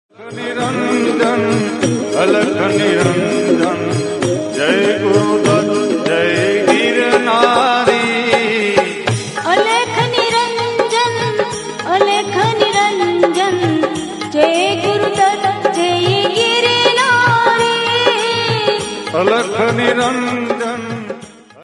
शिव भजन रिंगटोन